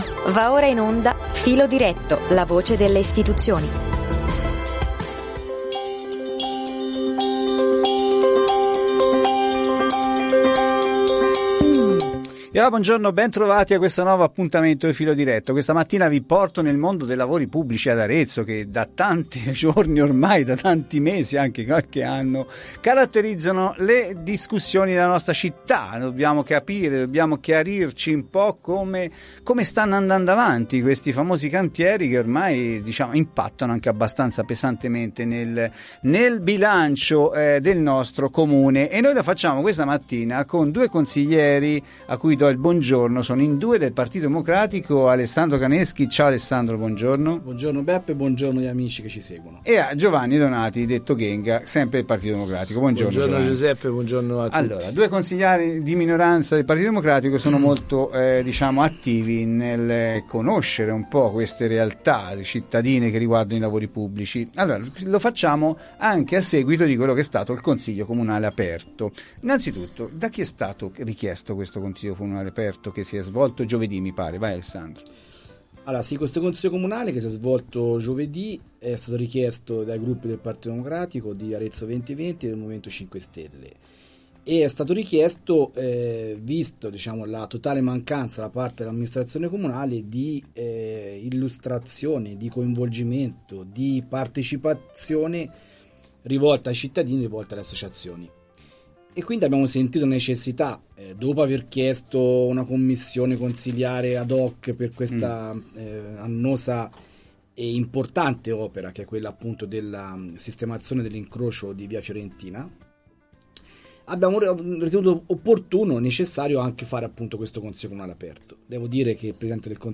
In studio i Consiglieri Comunali Alessandro Caneschi e Giovanni Donati.